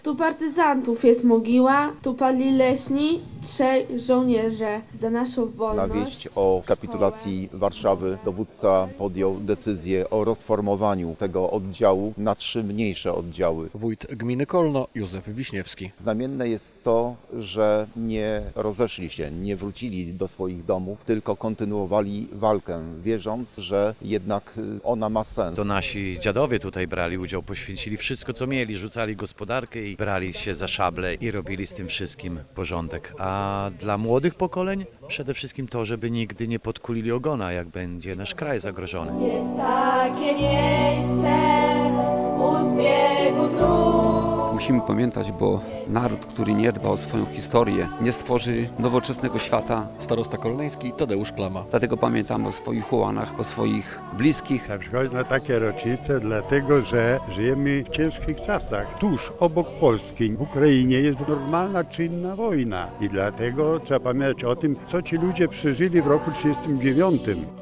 WIADOMOŚCI ŁOMŻA: Mieszkańcy Janowa oddali hołd żołnierzom 110.